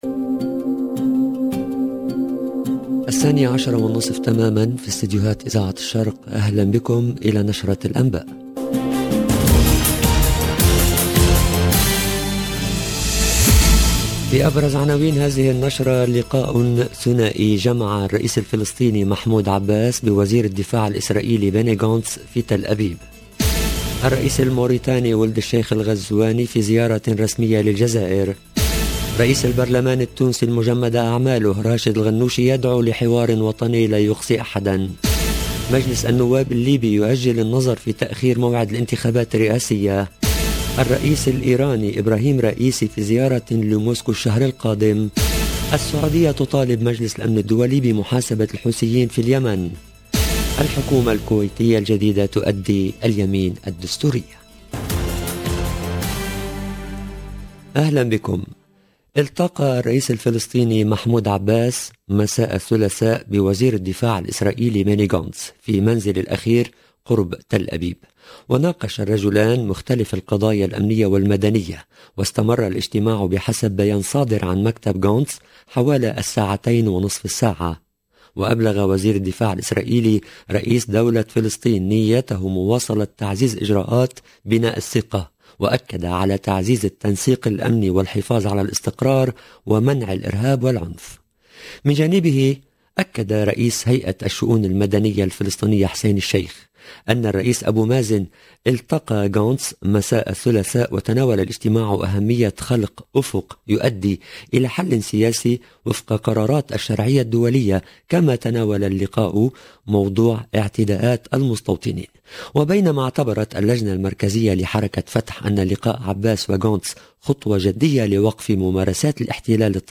LE JOURNAL DE MIDI 30 EN LANGUE ARABE DU 29/12/21